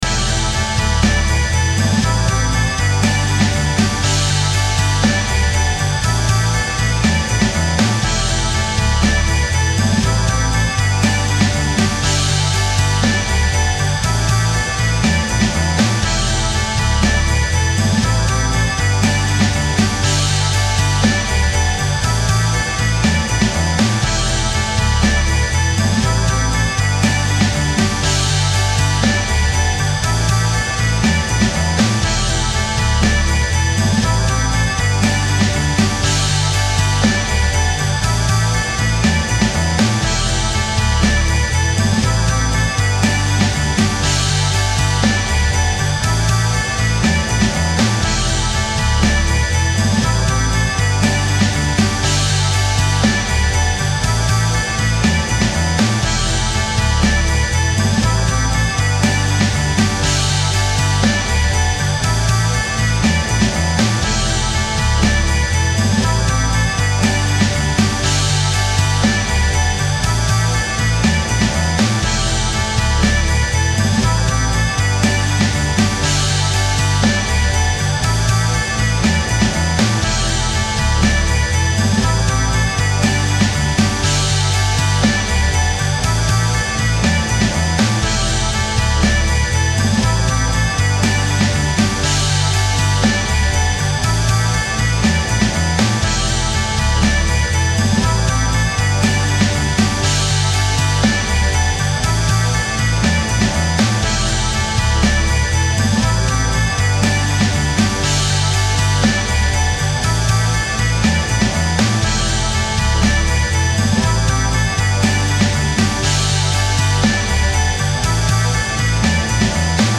Home > Music > Rock > Medium > Laid Back > Chasing